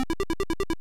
Charamario_MarioOriginal_walk2.wav